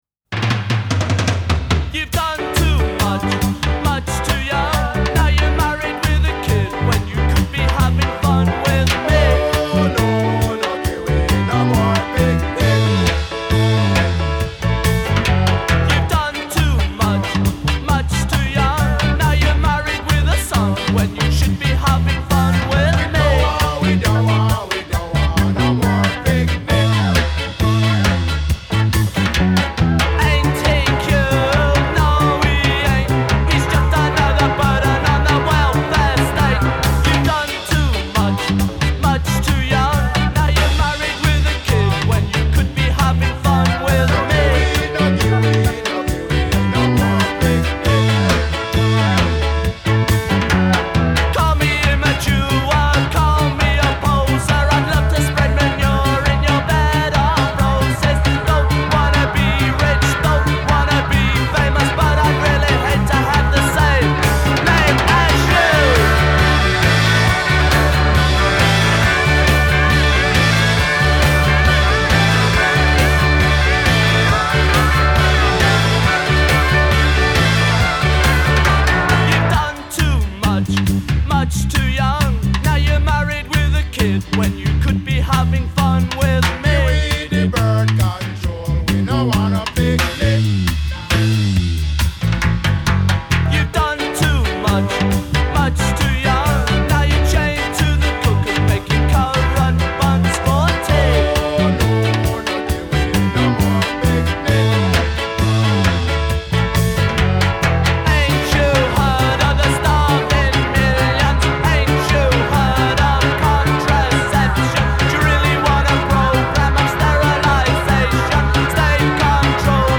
Here‘s the slower, dubbier album version